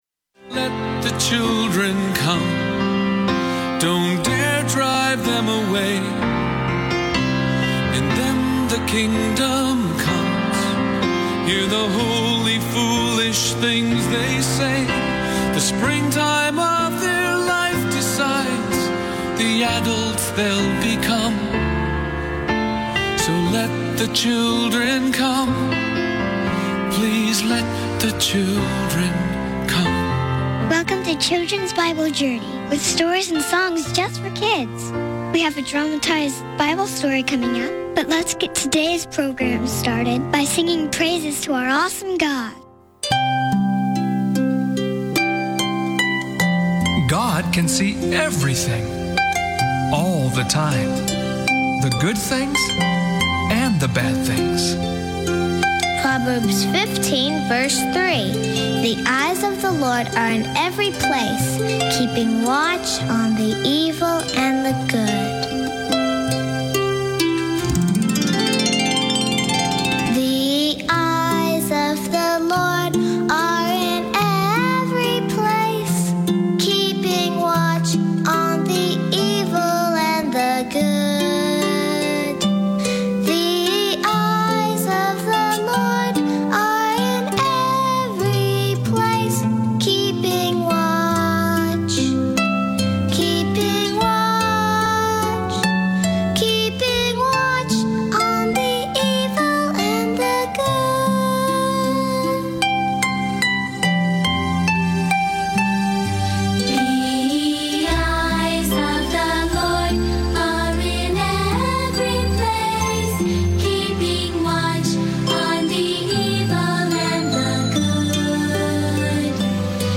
Enjoy a variety of programs for kids in less than 30 minutes.